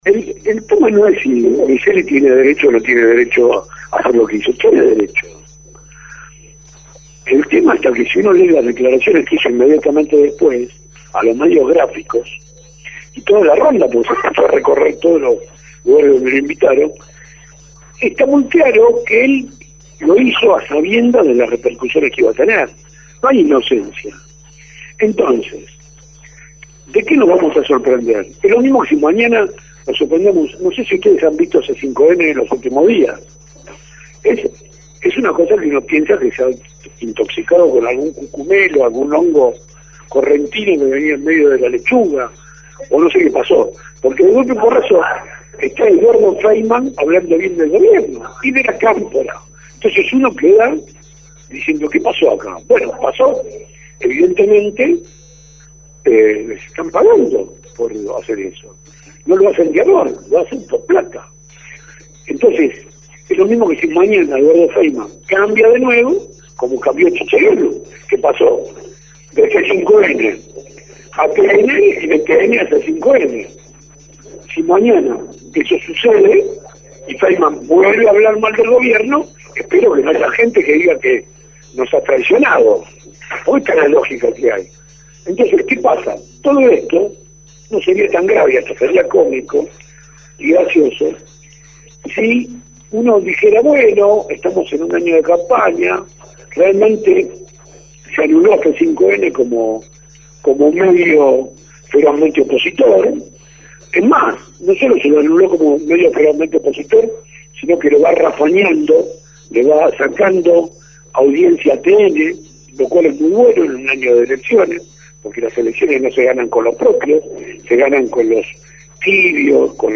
La charla fue trasmitida en vivo para La Señal.